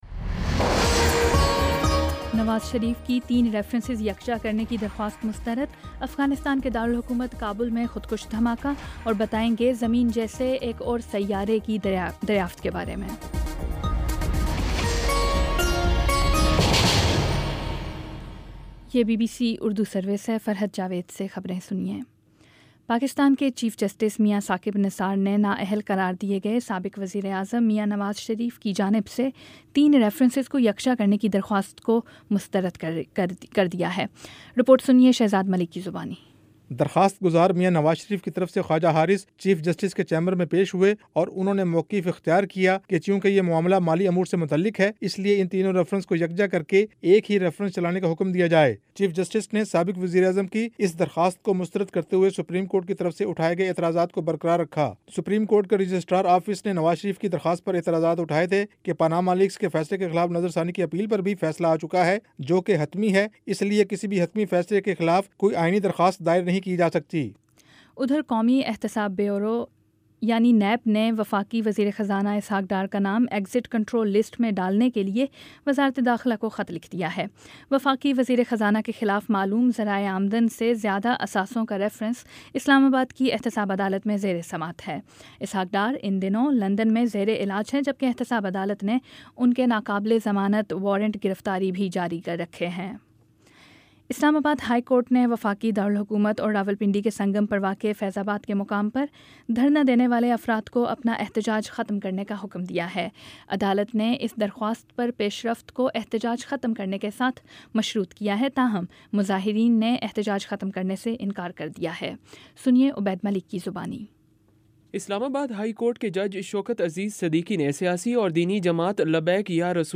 نومبر 16 : شام چھ بجے کا نیوز بُلیٹن